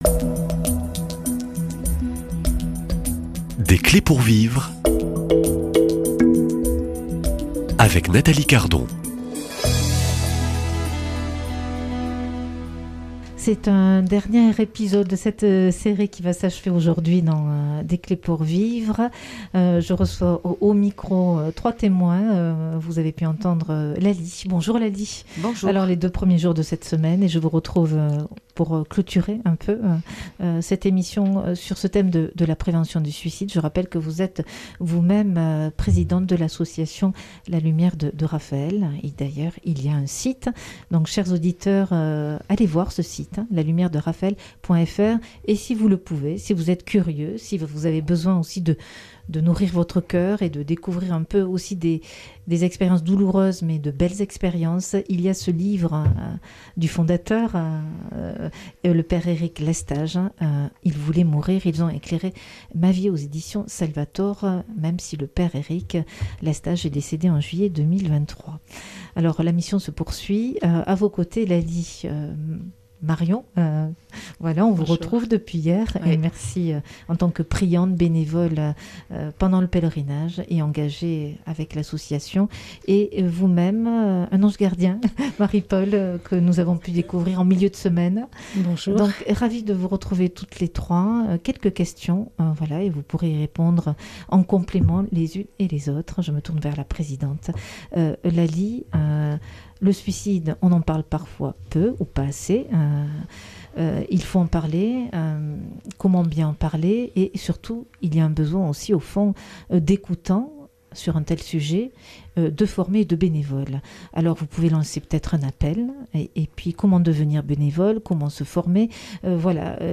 Trois paroles de femmes sur la prévention du suicide (Volet 5 )